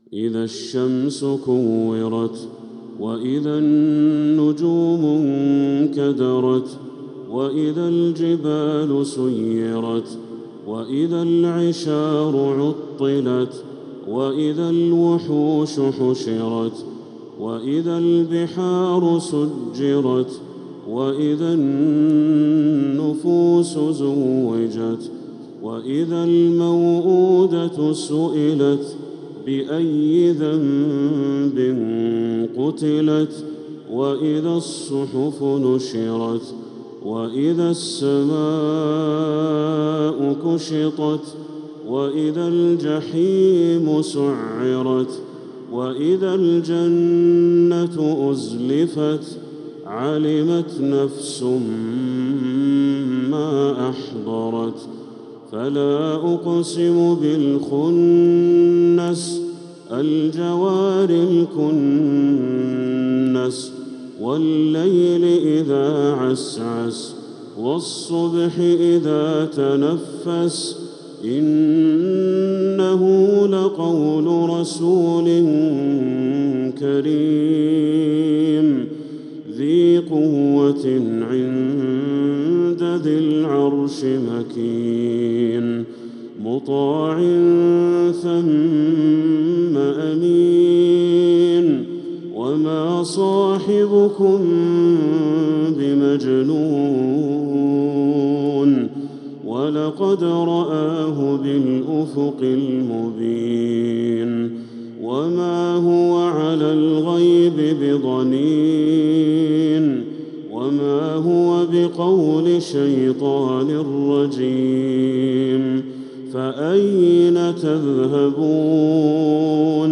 من الحرم المكي